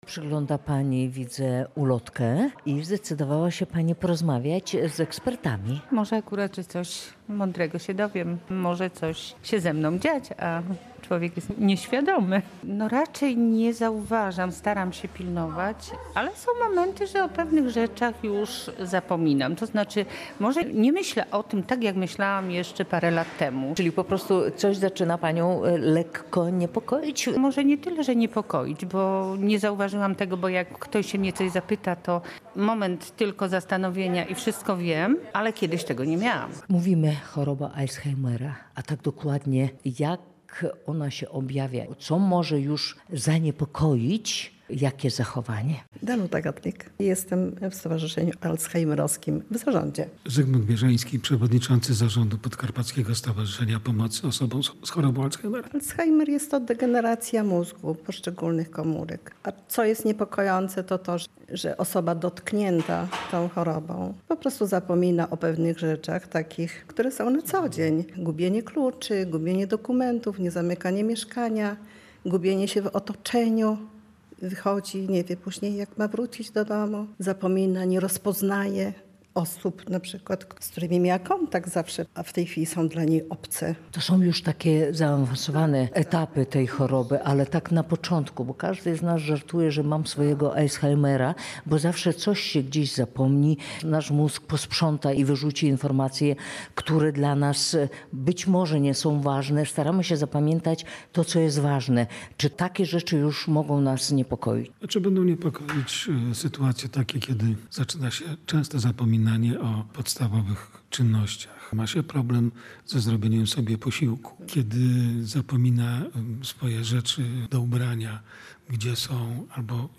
Relację